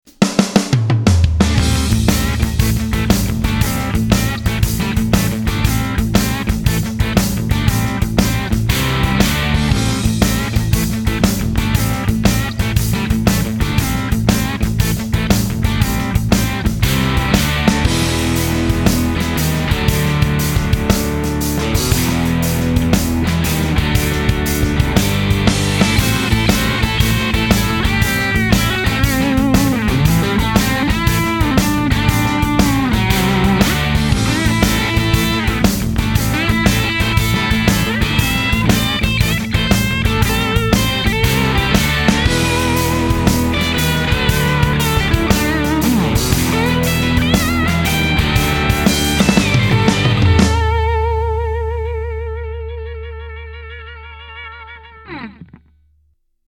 JTM de 1963 (original), Gibson SG 1965. SM57. Volume à 8, pas de pédale. http